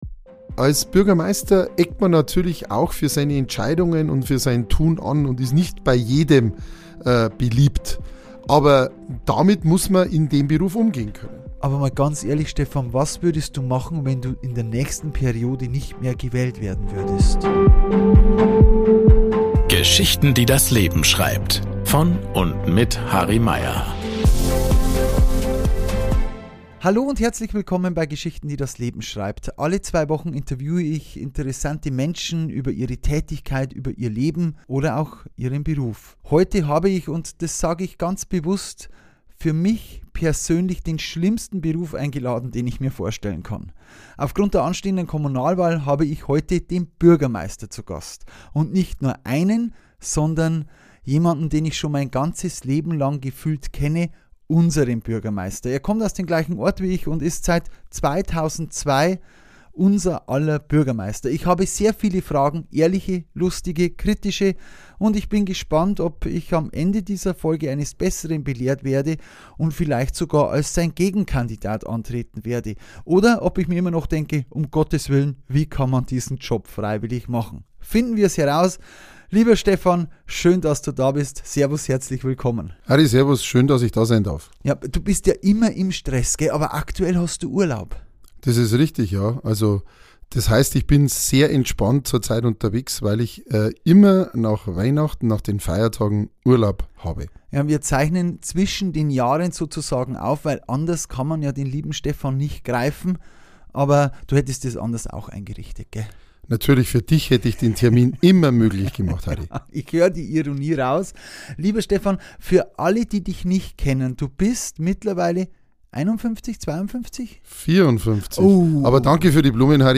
Wir sprechen über seinen Weg ins Amt, über Entscheidungen, die niemandem gefallen – und über die vielen kleinen Begegnungen, die diesen Beruf so besonders machen. Es geht um politische Verantwortung, um Kritik und Politikverdrossenheit, aber auch um Humor, Bürgernähe, absurde Anfragen und die Frage, wie viel Mensch eigentlich hinter dem Amt steckt. Ein ehrliches Gespräch über einen Beruf, den viele kritisieren – aber nur wenige freiwillig machen.